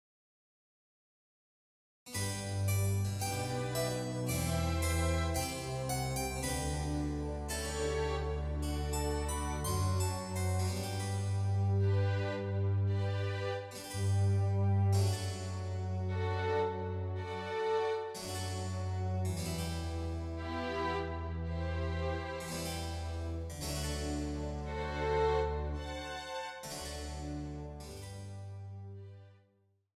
Saxophone Ténor